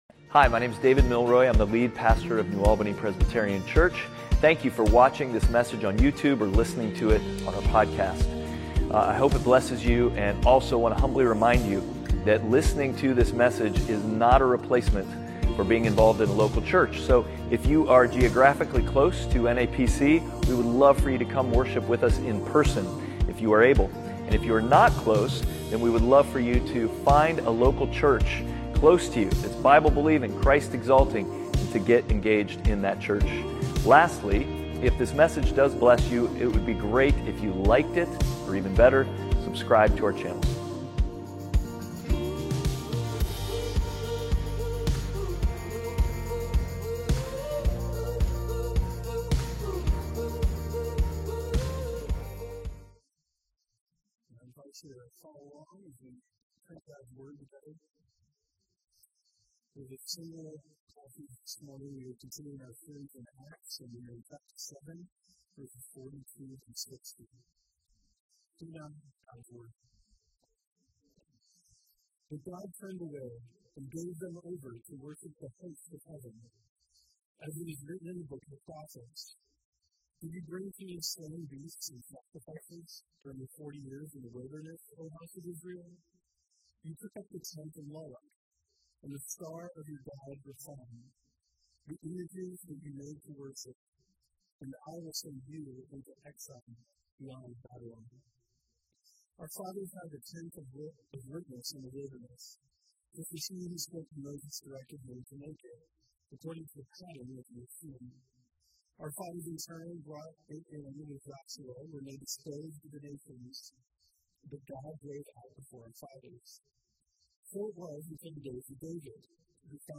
Passage: Acts 7:42-60 Service Type: Sunday Worship